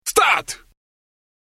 人の声（23KB）